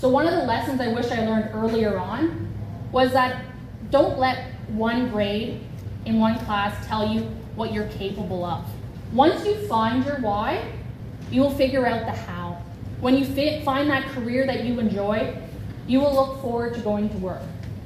gave a keynote speech at the event where she urged students to keep their options open as adults until they figure out what career they enjoy.